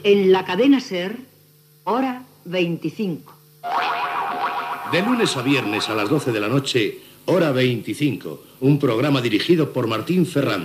Identificació del programa